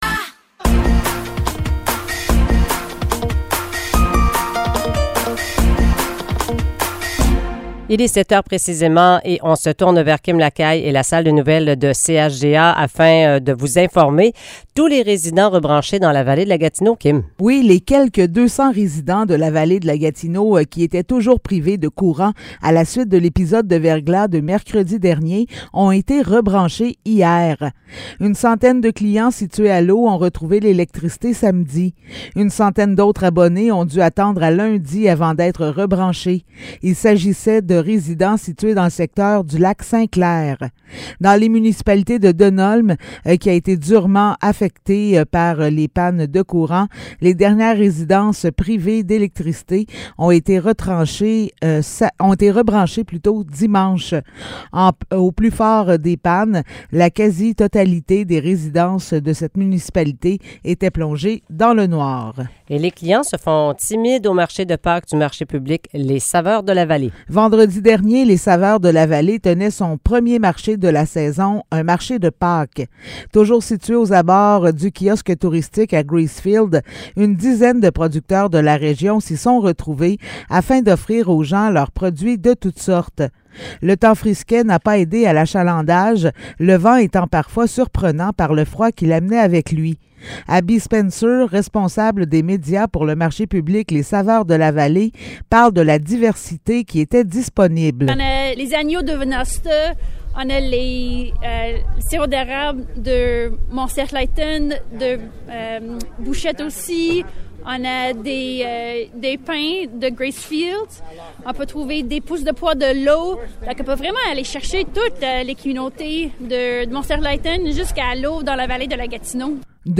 Nouvelles locales - 11 avril 2023 - 7 h